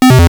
pc_off.wav